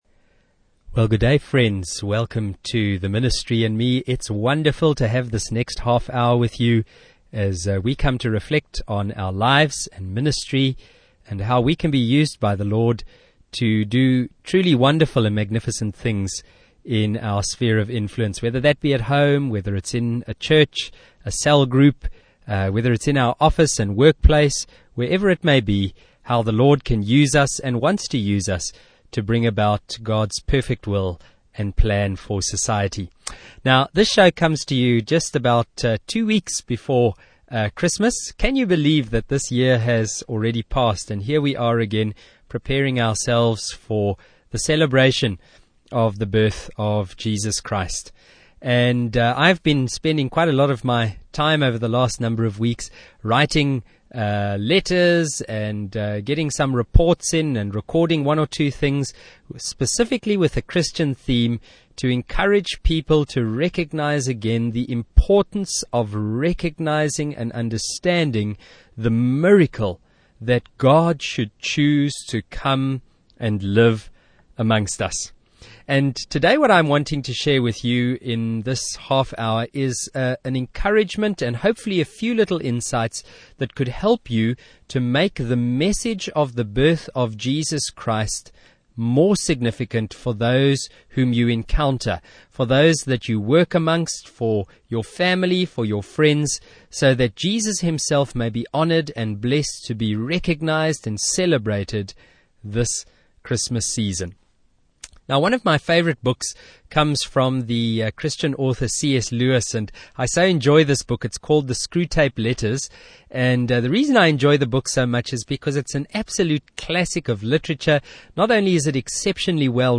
Technorati tags: Christmas resources , Christmas , Radio Broadcast , Radio Pulpit , Zimbabwe